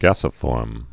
(găsə-fôrm)